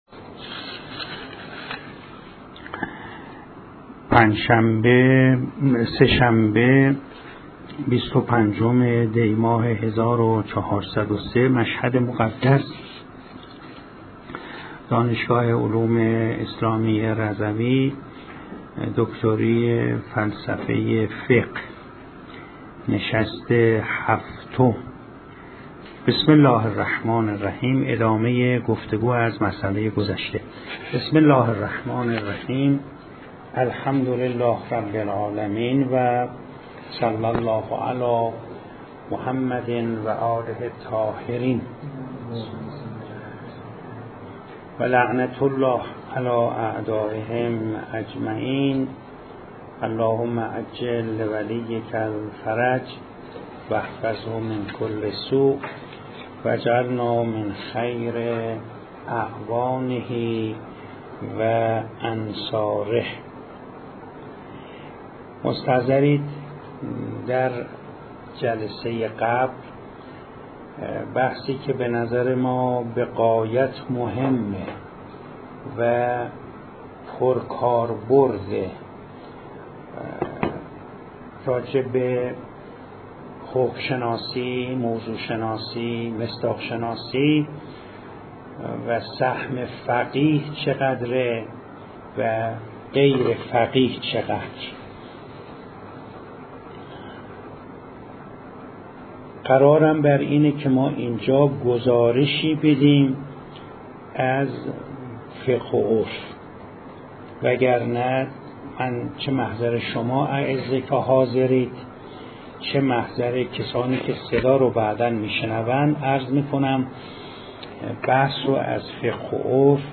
مشهد مقدس - دانشگاه علوم اسلامی رضوی جلسه هفتم دکتری فلسفه فقه 25 دی 1403 بیان موضوع و تشخیص مصداق، فقیه یا عرف ادامه جلسه قبل براساس فقه و عرف صص353 تا 392 همراه با ارائه کنفرانس با عنوان گستره اجتهاد و فقه از فقه و مصلحت صص 224 تا 235